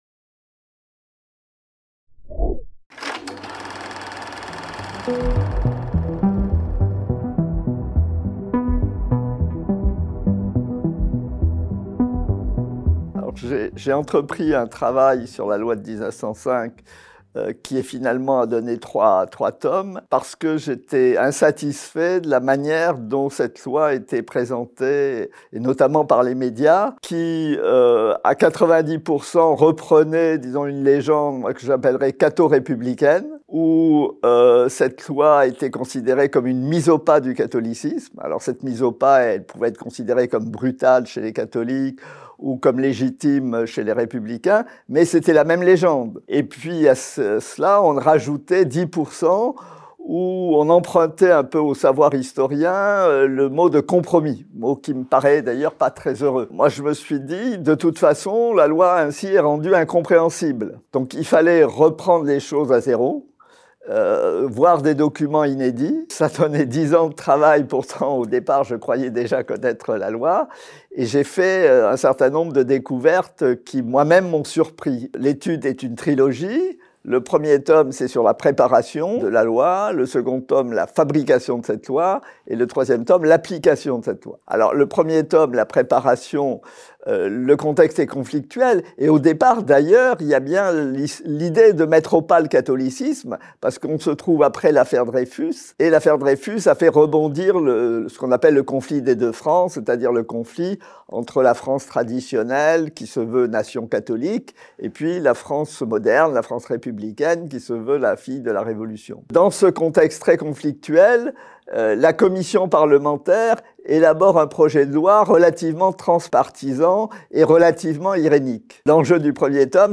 Interview de Jean Baubérot, dans le cadre de la sortie de son ouvrage : La loi de 1905 n'aura pas lieu. Histoire politique des Séparations des Églises et de l'État (1902-1908). Tome III